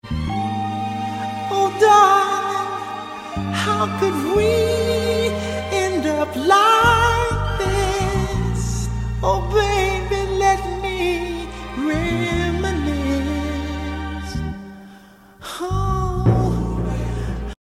Ahh sound effects free download